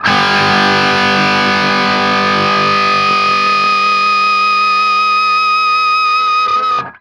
TRIAD G   -L.wav